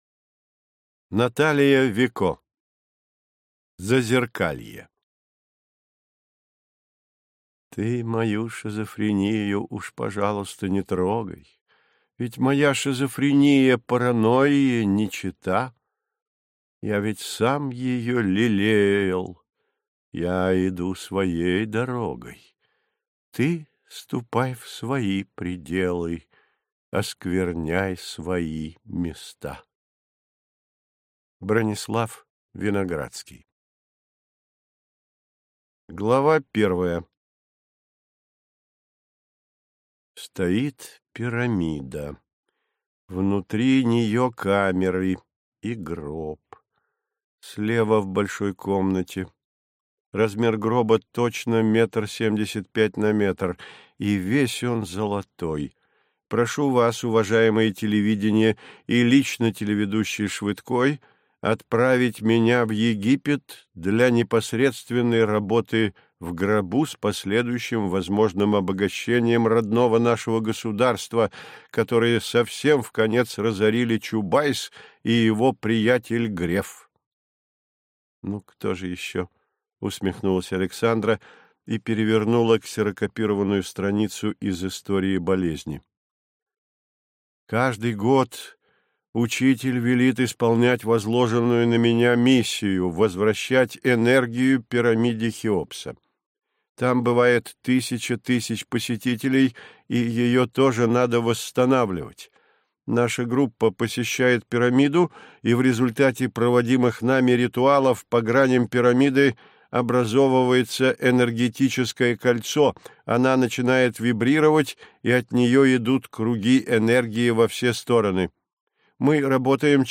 Аудиокнига Зазеркалье. Записки психиатра | Библиотека аудиокниг